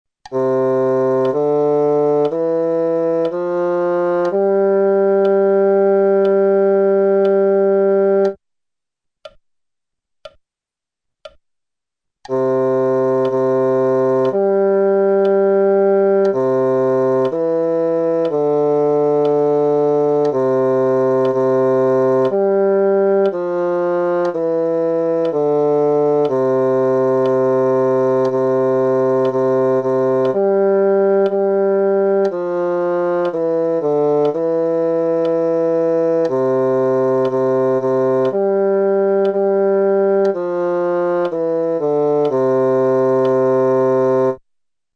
Prima dei dettati veri e propri, sentirai le note che verranno proposte, seguite da una battuta vuota scandita nella divisione dal metronomo.
Note: Do - Re - Mi - Fa - Sol
Tempo: 4/4